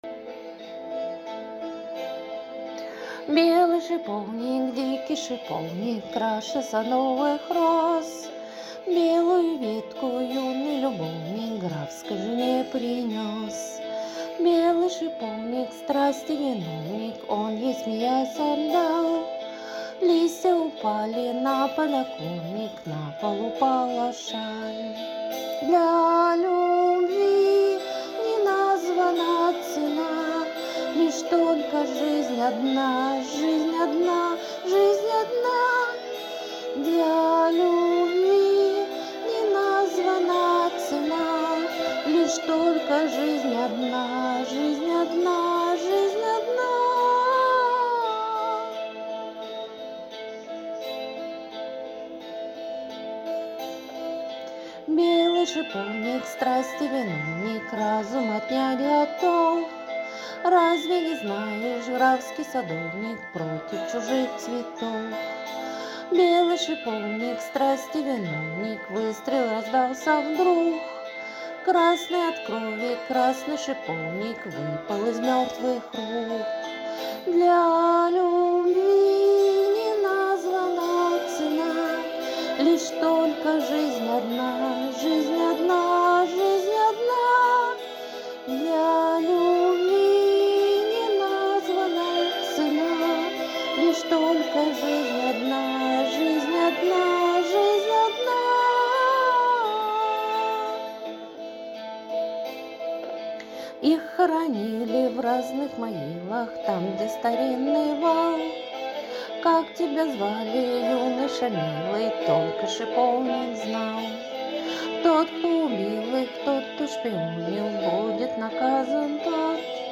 но предупреждаю, у меня бронхит, дыхалки не хватает((